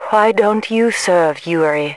心控平民